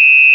pfiff.au